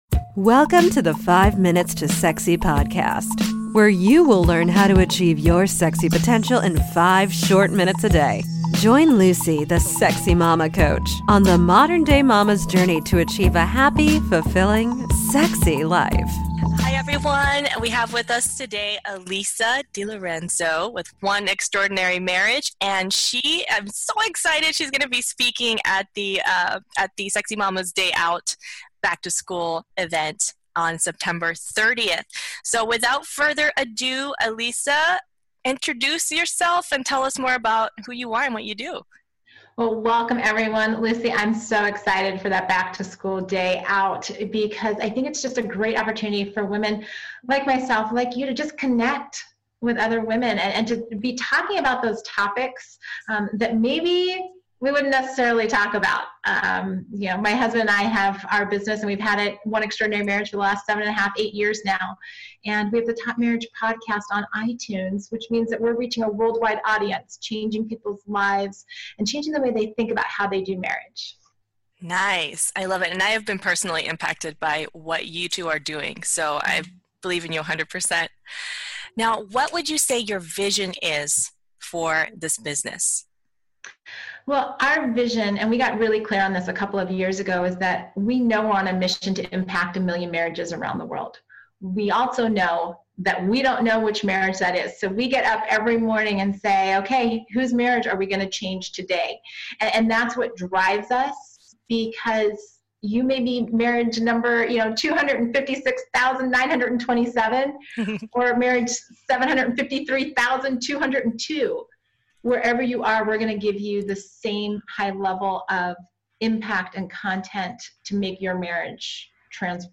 Speaker Spotlight